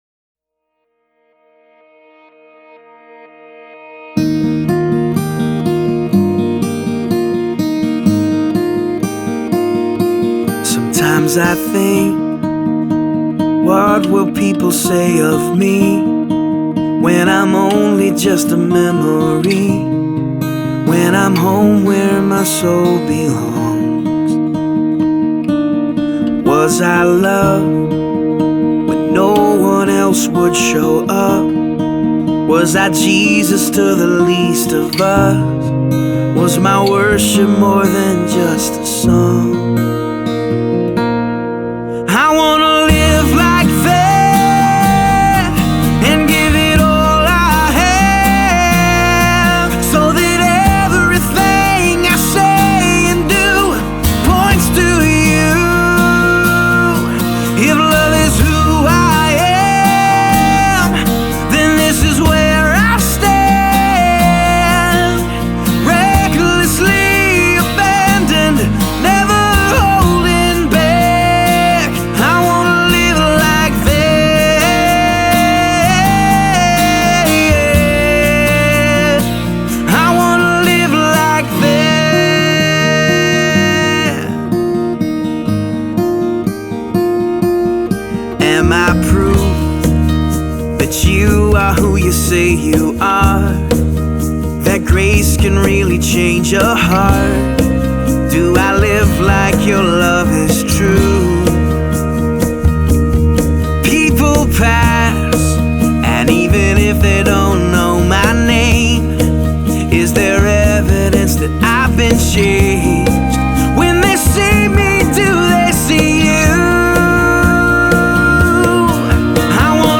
Sunday Worship – First Song: